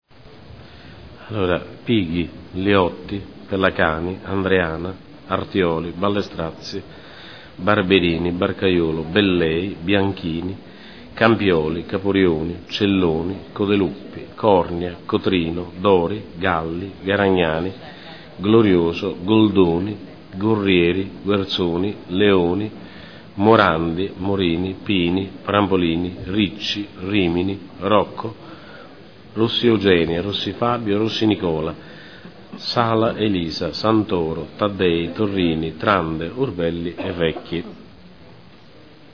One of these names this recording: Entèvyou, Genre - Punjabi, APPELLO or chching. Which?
APPELLO